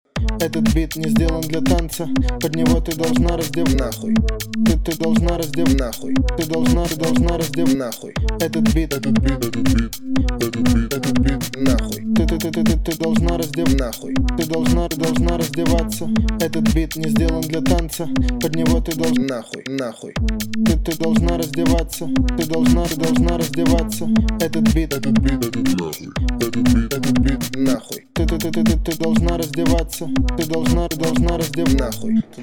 панк